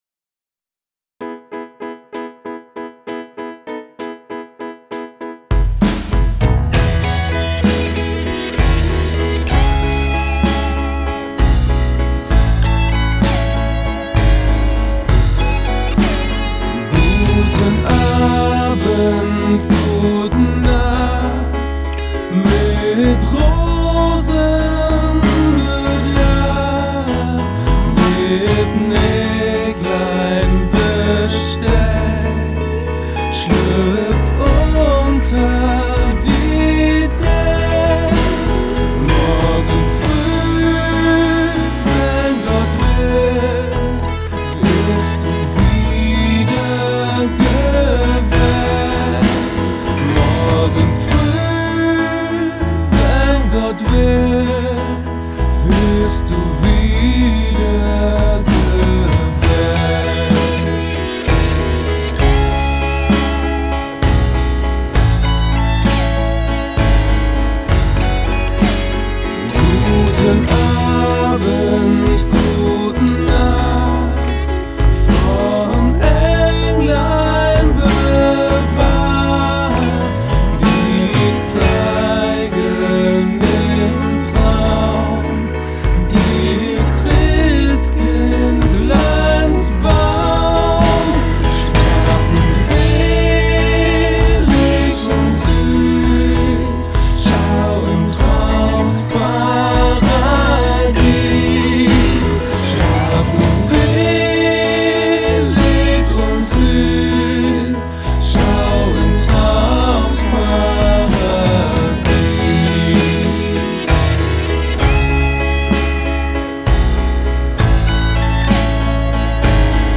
Schlaflieder